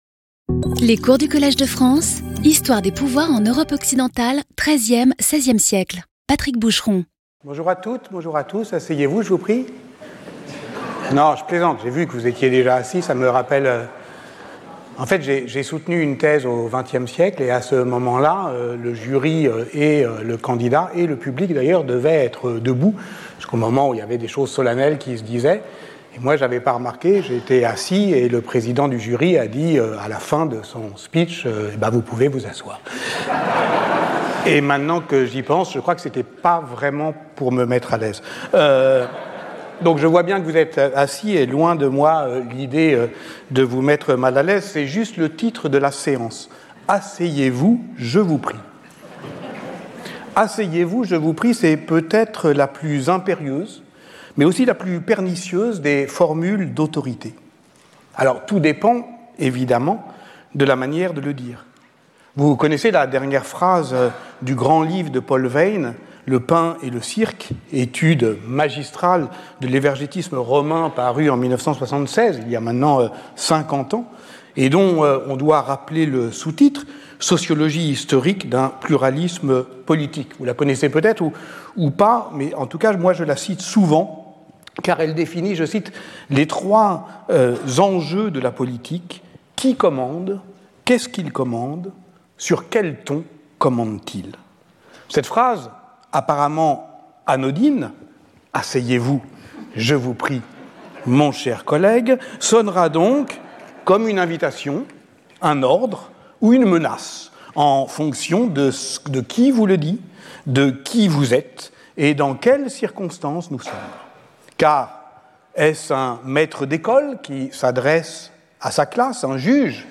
Speaker(s) Patrick Boucheron Professor at the Collège de France